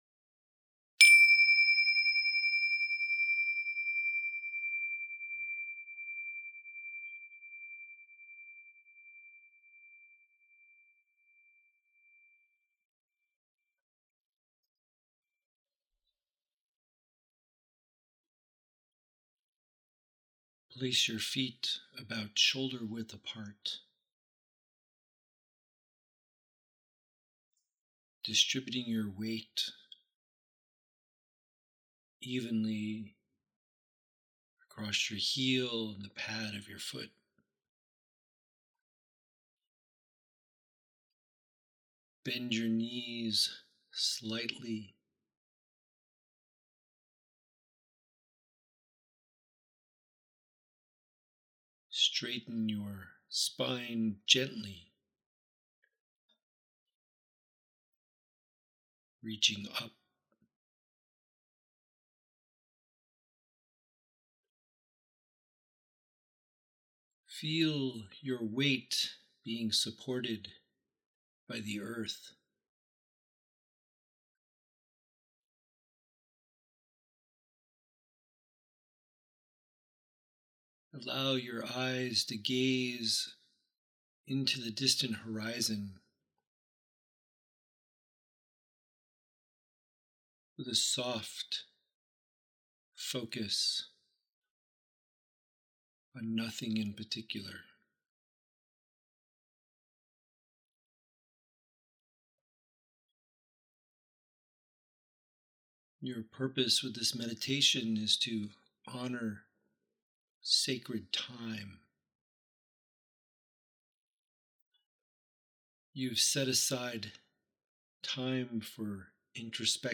If you like, you can play this audio recording of me guiding you through the meditation.